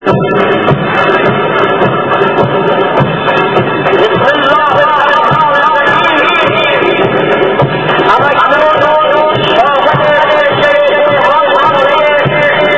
Da vi alligevel ikke kan komme til at handle og aldrig tidligere har oplevet en sådan parade, går vi hen og stiller os sammen med flokken af andre nysgerrige.
Den ene kolonne af unge mænd efter den anden marcherer forbi de vigtige herre på podiet til tonerne af marchmusik!